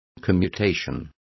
Complete with pronunciation of the translation of commutations.